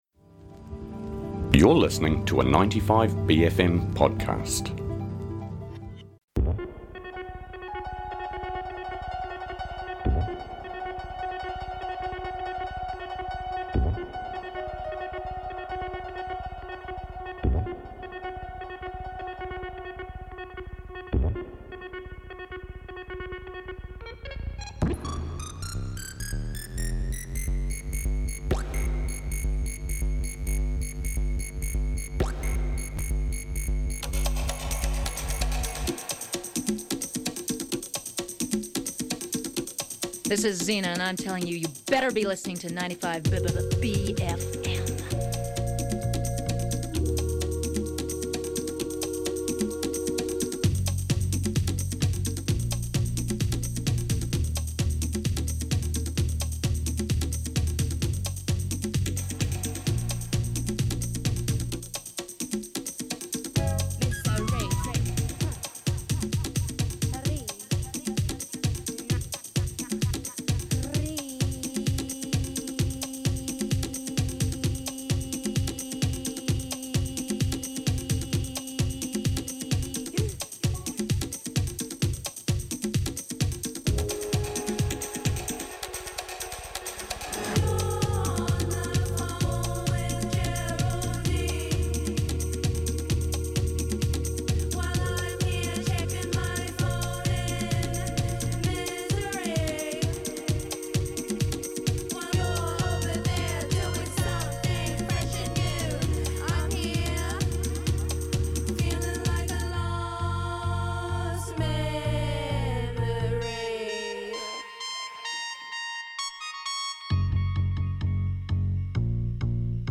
dials in to chat about her upcoming gig this Saturday, and her recently released album Mosaics.